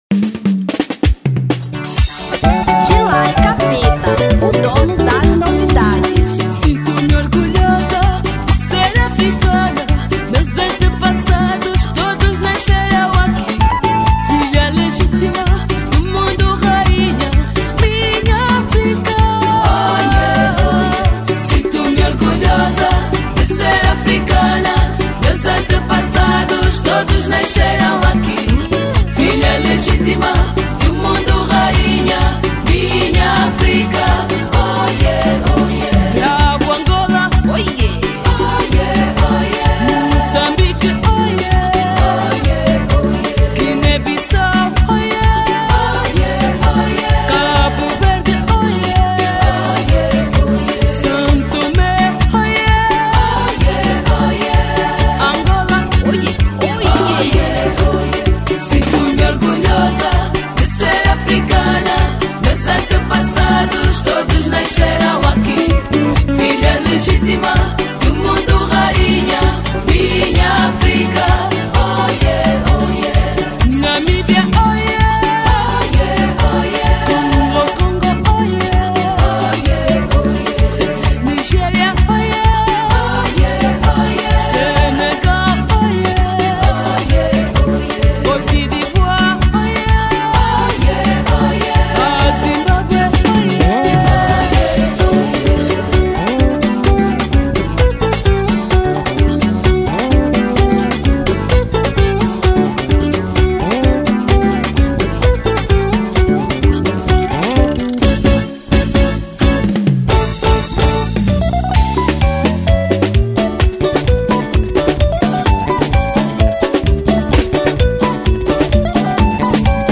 Semba 2000